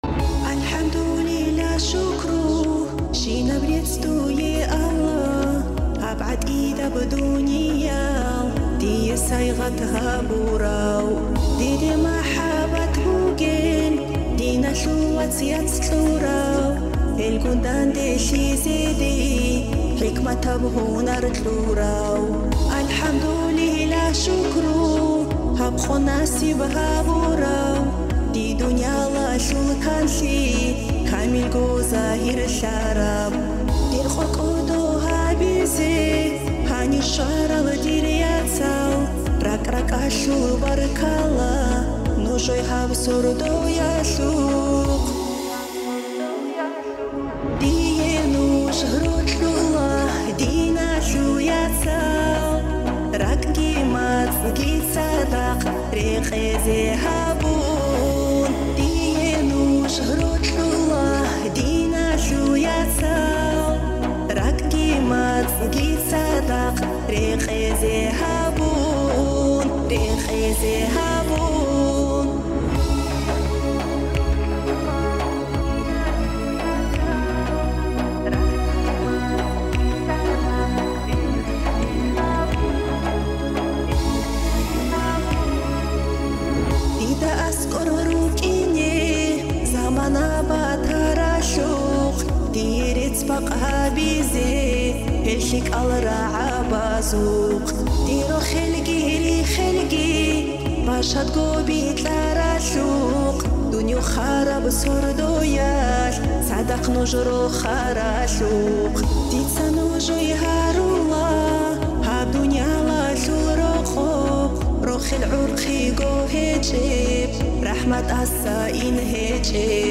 Nasheed of Dagestan